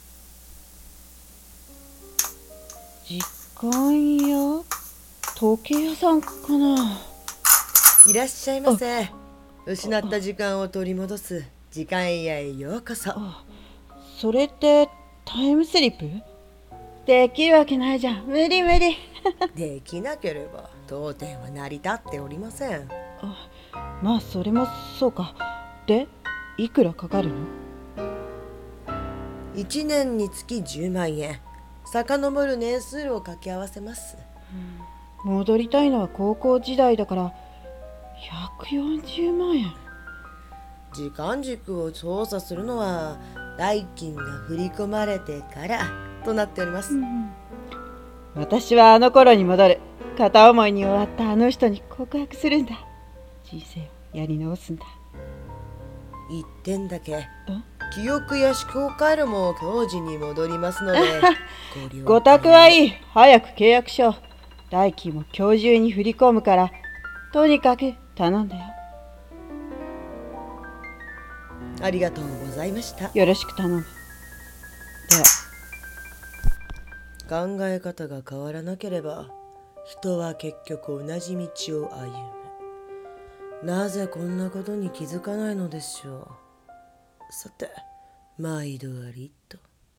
2人声劇「時間屋」